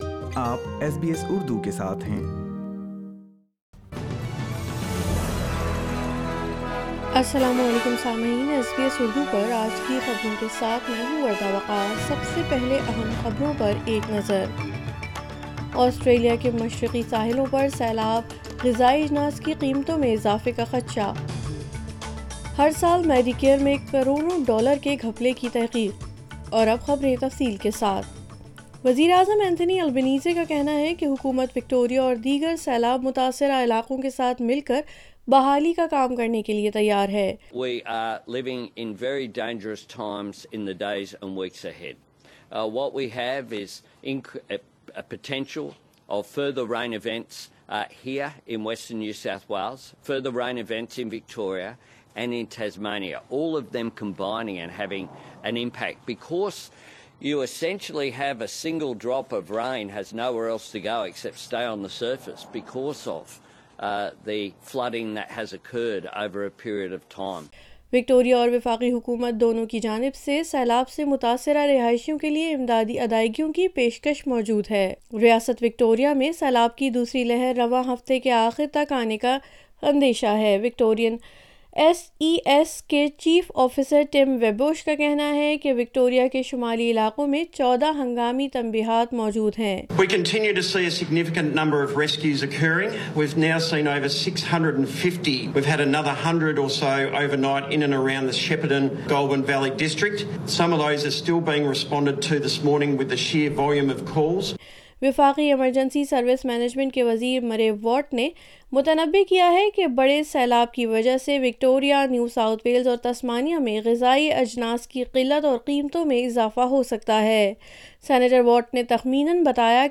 Urdu News Monday 17 October 2022